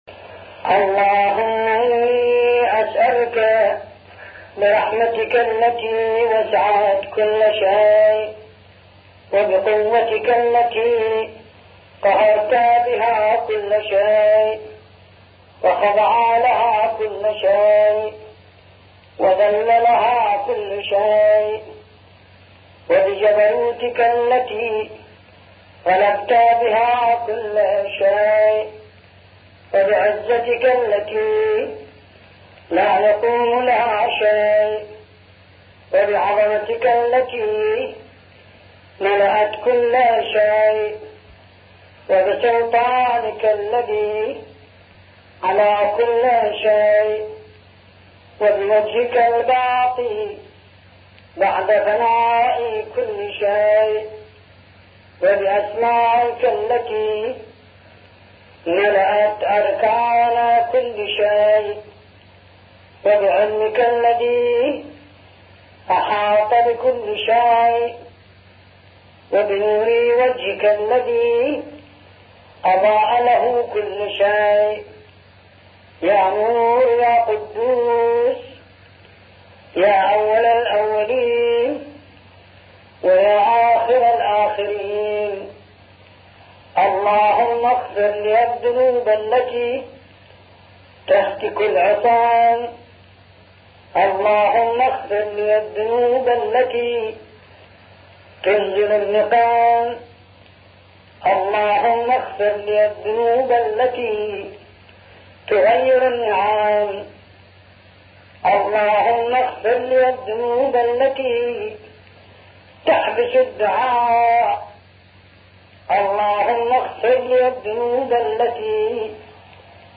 ملف صوتی دعاء كميل بصوت حمزه الزغير
الرادود حمزة الصغير